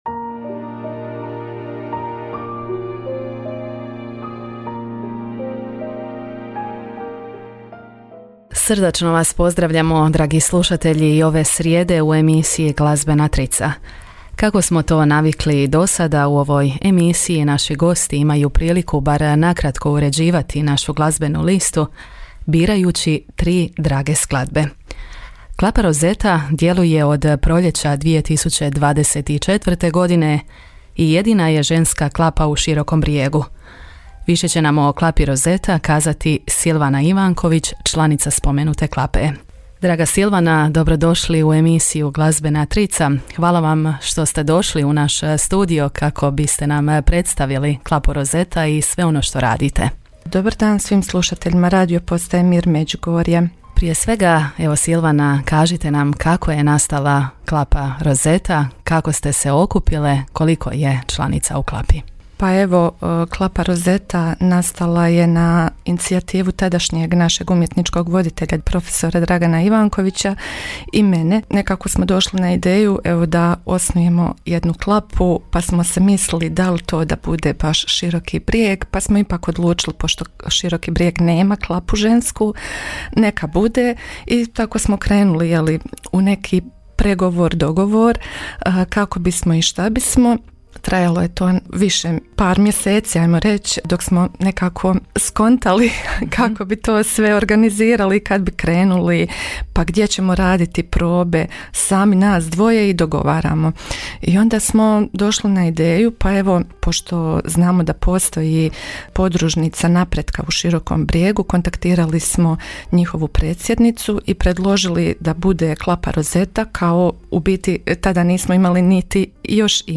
Emisija je ovo u kojoj naši gosti na kratko uređuju naš glazbeni program, birajući tri drage skladbe.